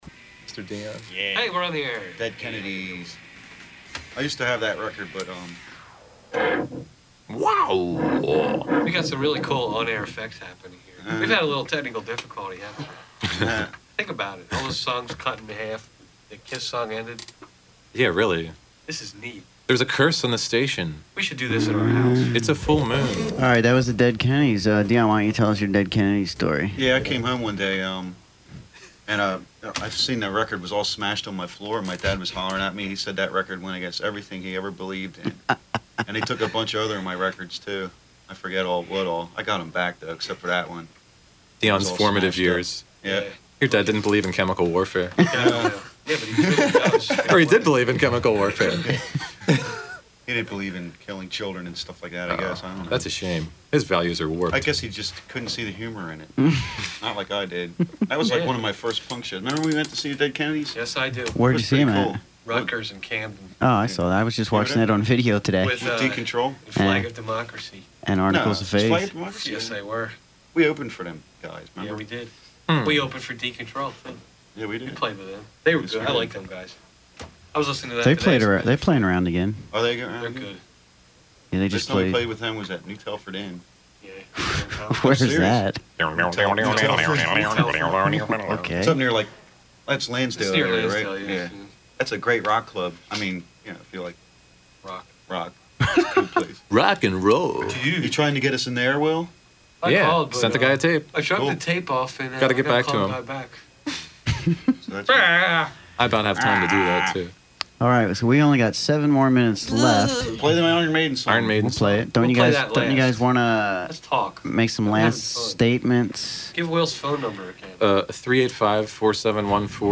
WXAC Interview 11-03-95